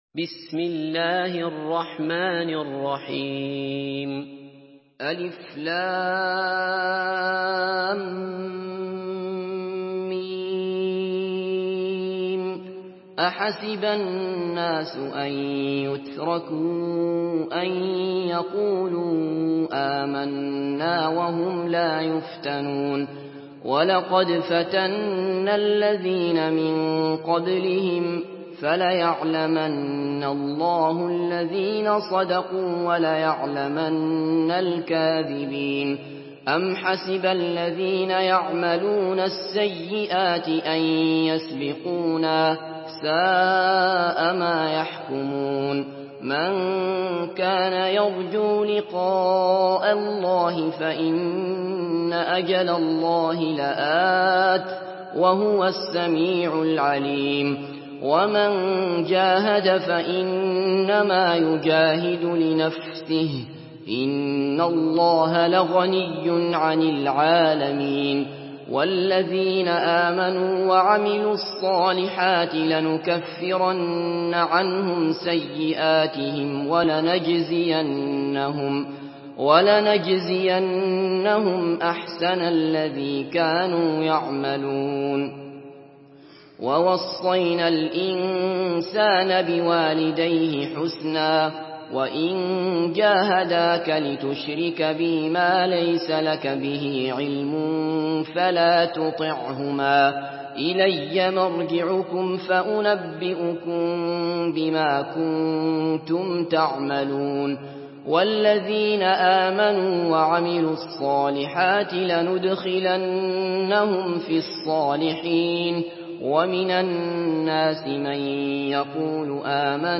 Surah Al-Ankabut MP3 in the Voice of Abdullah Basfar in Hafs Narration
Surah Al-Ankabut MP3 by Abdullah Basfar in Hafs An Asim narration.
Murattal Hafs An Asim